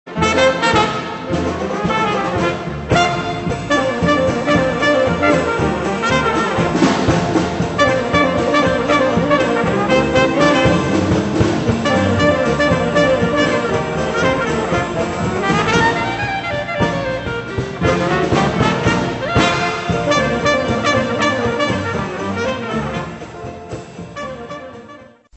jazz number